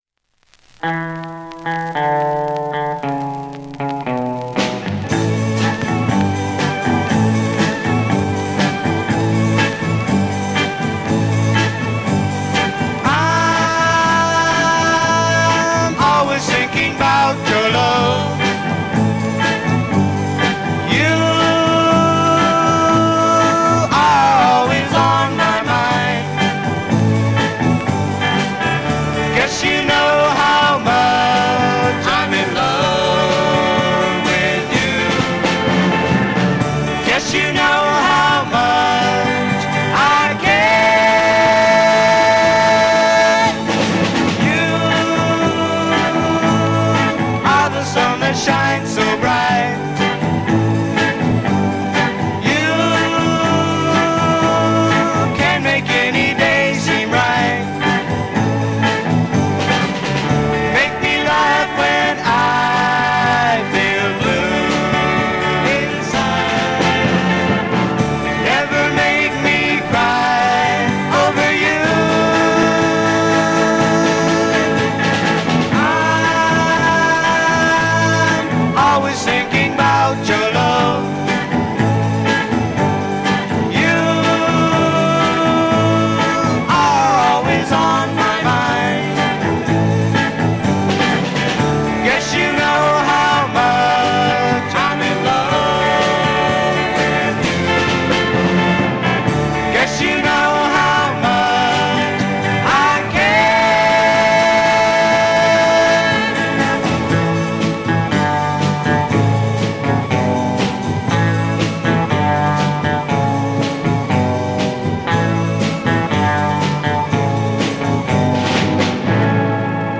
drums
organ